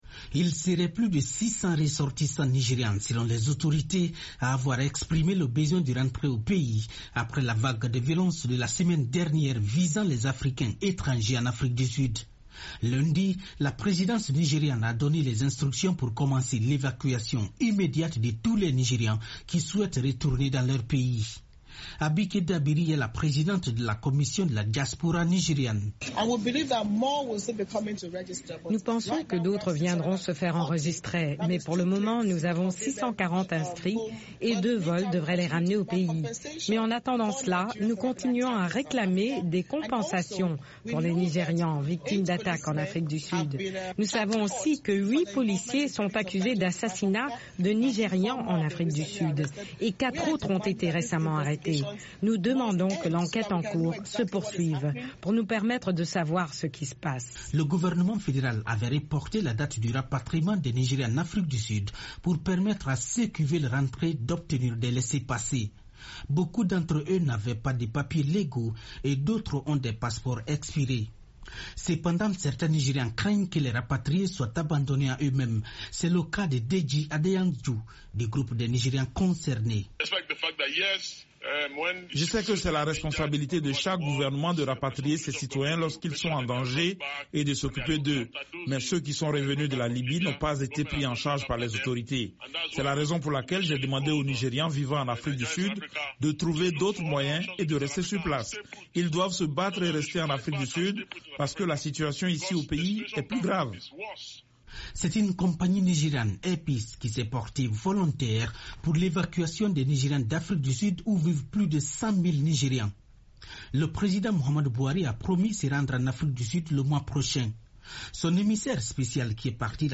Les premiers convois de Nigérians fuyant les violences en Afrique du Sud sont attendus mercredi au Nigeria. A Abuja on s’inquiète de leur prise en charge puisque le gouvernement n’a prévu aucune assistance. Le Reportage